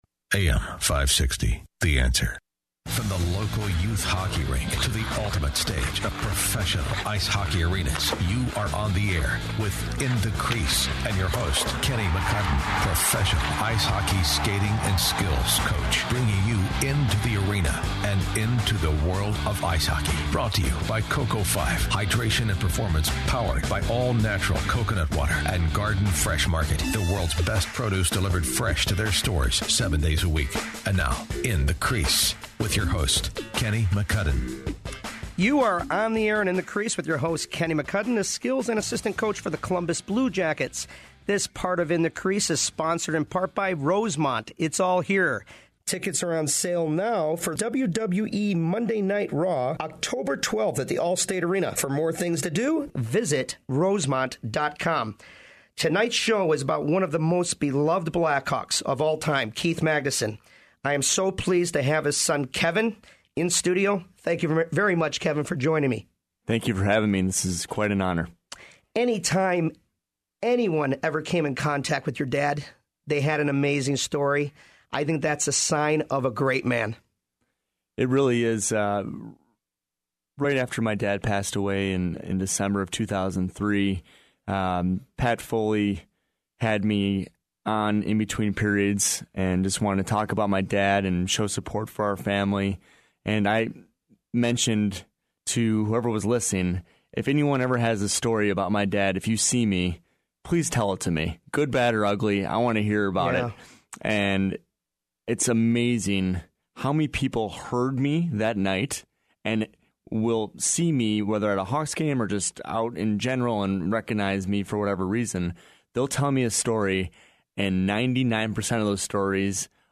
Radio Interview AM560 The Answer